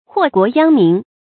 注音：ㄏㄨㄛˋ ㄍㄨㄛˊ ㄧㄤ ㄇㄧㄣˊ
禍國殃民的讀法